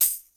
hat_05.wav